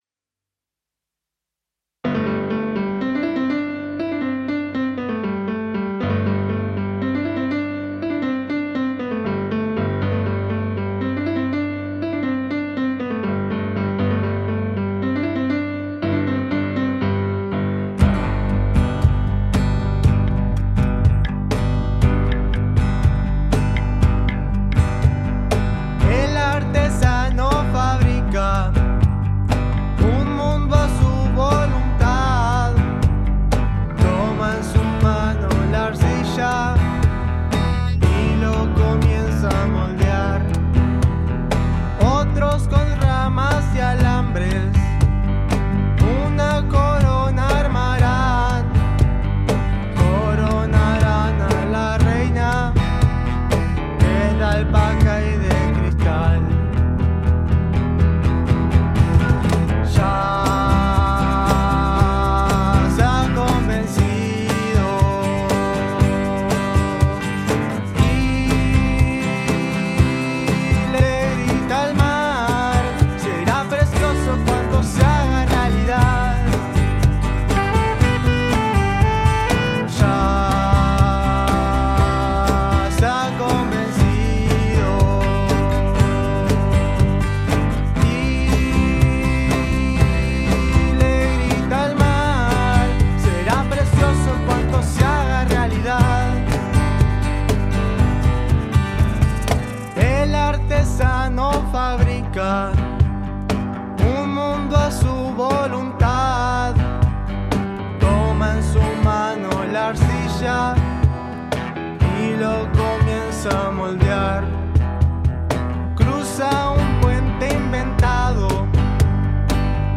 Desde la Gerencia de Formación Cultural, con el objetivo de potenciar las instancias formativas y en coordinación con la Usina Cultural de Parque del Plata, se grabaron productos musicales de los participantes de los talleres que se llevan a cabo en el departamento.
14._comunabanda_el_artesano_salinas.mp3